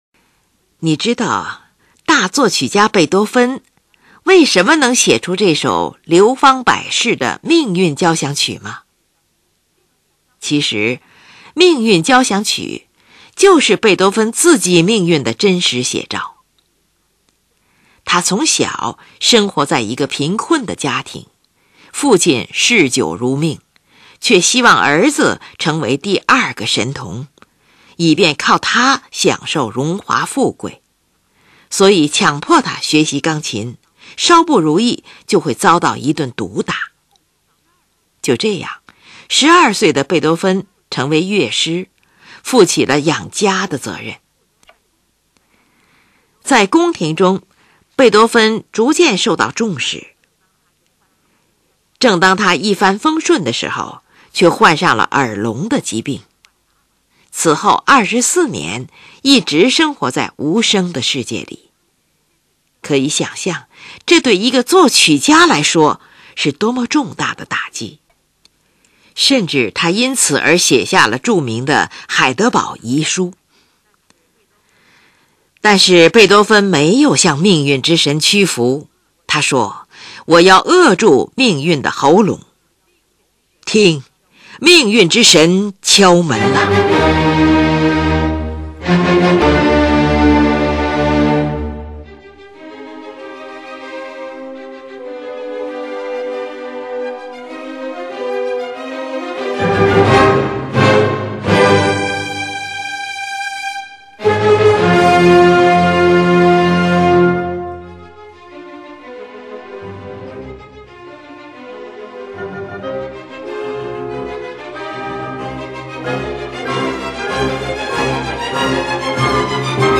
试听：第一乐章 片段